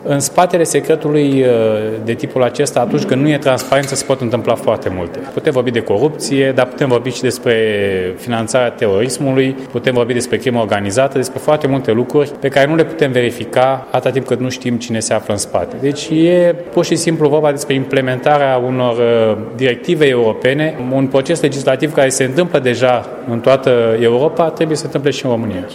Europarlamentarul Cătălin Ivan a declarat, joi, la Tîrgu-Mureș, în cadrul unei dezbateri cu titlul „Panama Papers, Paradise Papers- Impactul asupra economiei şi reglementărilor europene”, că România trebuie să se alinieze celorlalte state pentru eliminarea acționariatului ascuns.
Evenimentul a fost găzduit de Facultatea de Stiinte Economice, Juridice si Administrative a Universității ”Petru Maior” din Tîrgu-Mureș.